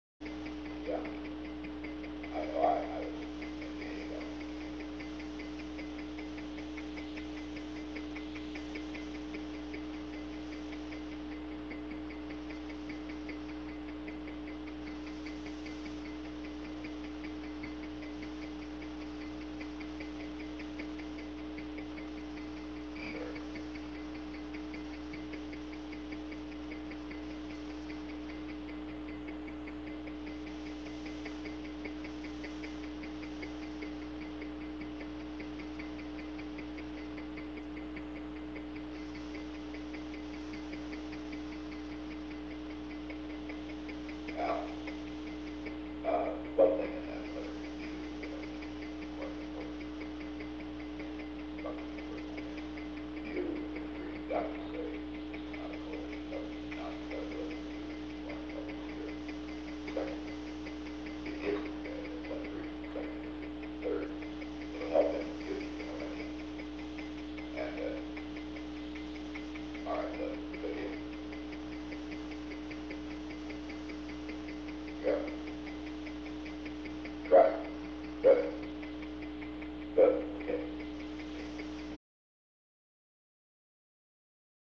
Secret White House Tapes
Conversation No. 377-14
Location: Executive Office Building
Alexander M. Haig, Jr. talked with the President.